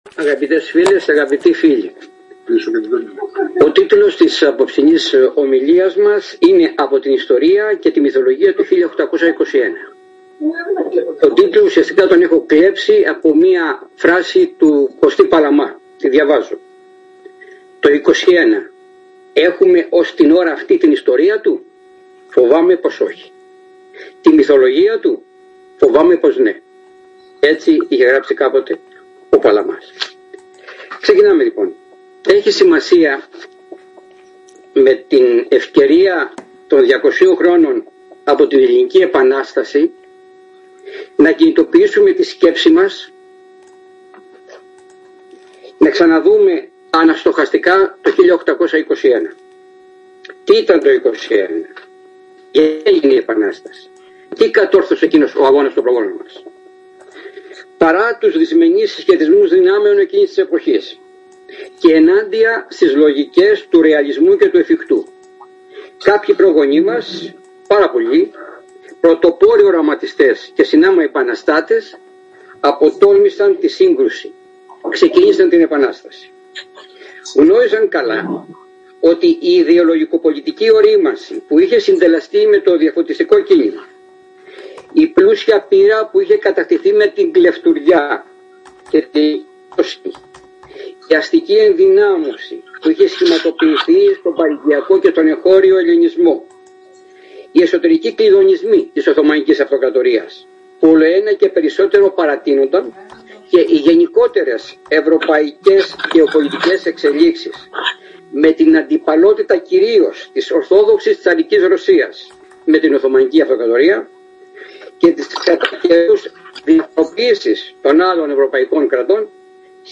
Ενδιαφέρουσα συζήτηση έγινε για το “κρυφό σχολειό”